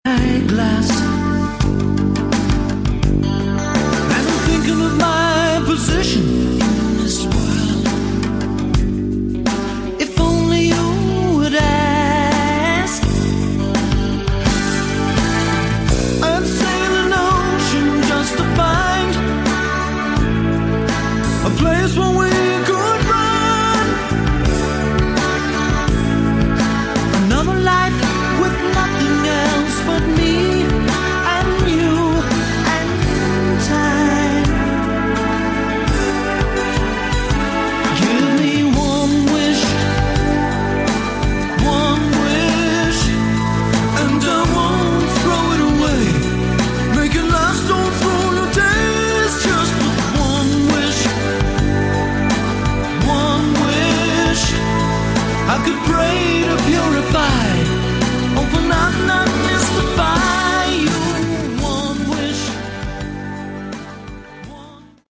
Category: Hi-Tech AOR